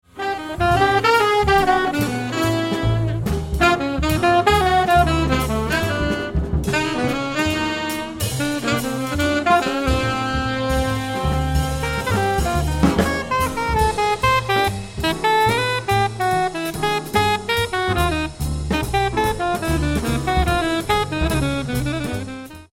Recorded live at the Y Theatre Leicester November 2007